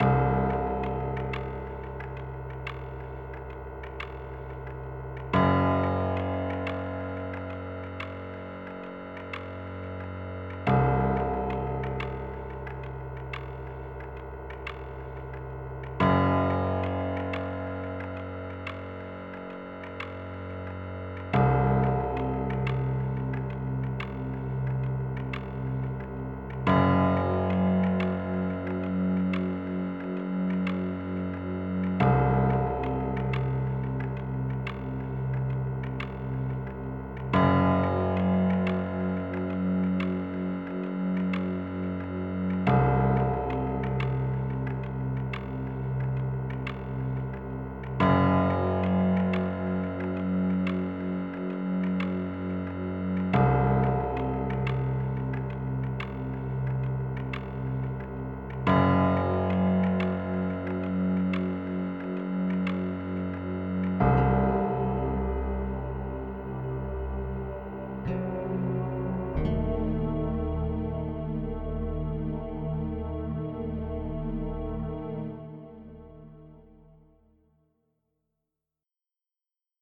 Type General MIDI